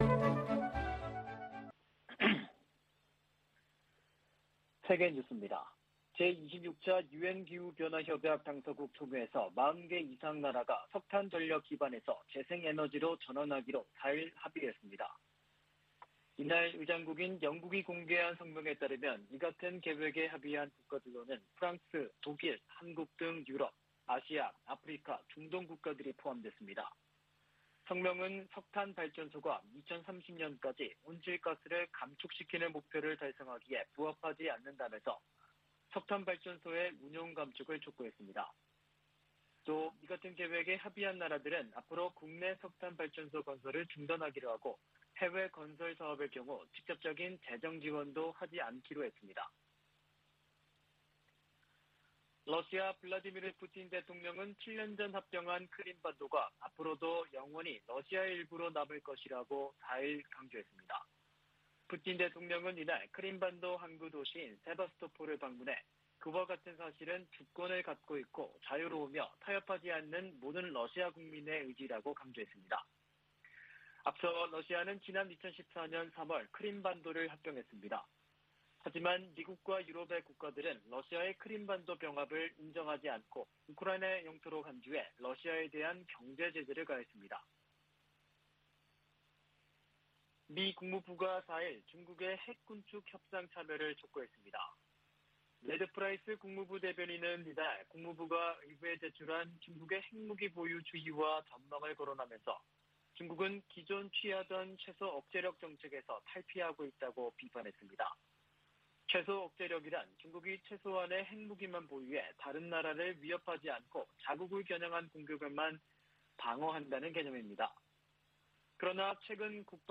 VOA 한국어 아침 뉴스 프로그램 '워싱턴 뉴스 광장' 2021년 11월 6일 방송입니다. 북한의 불법 무기 프로그램 개발을 막기 위해 전 세계가 유엔 안보리 대북제재를 이행할 것을 미 국무부가 촉구했습니다. 또한 국무부는 북한의 사이버 활동이 전 세계에 위협인 만큼 국제사회 협력이 필수적이라고 밝혔습니다. 뉴질랜드가 안보리 대북제재 위반 활동 감시를 위해 일본 해상에 항공기를 배치합니다.